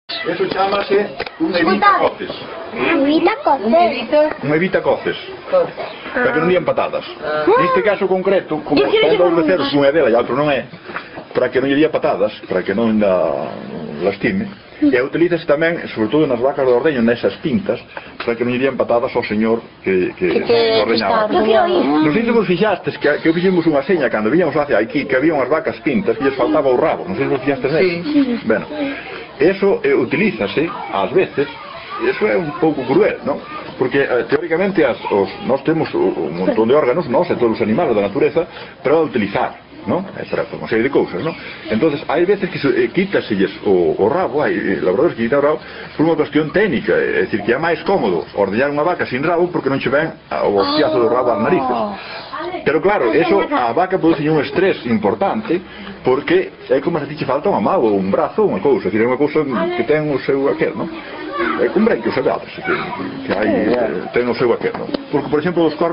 Os becerriños mamando